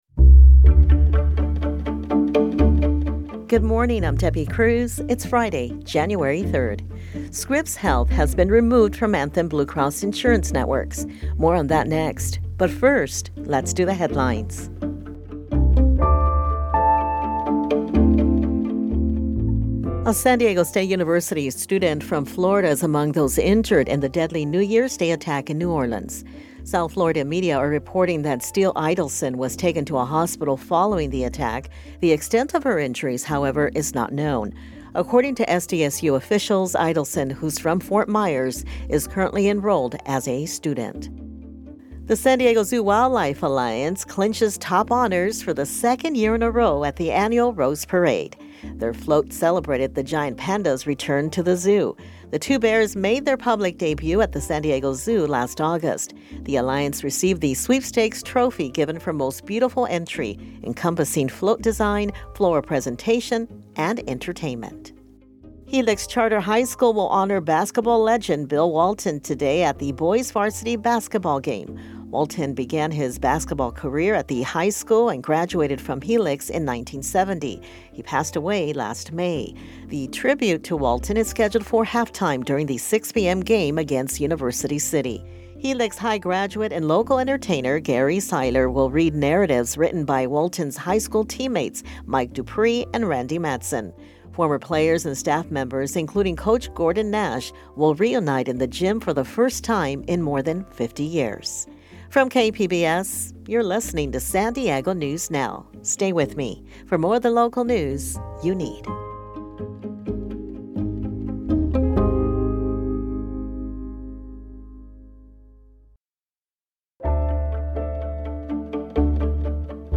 San Diego news; when you want it, where you want it. Get local stories on politics, education, health, environment, the border and more.